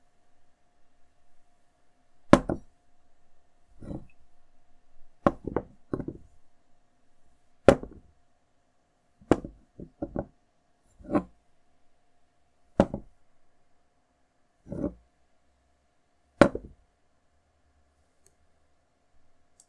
Glass on wood
Bottle Cup Desk Ding Drink Glass Impact Ring sound effect free sound royalty free Sound Effects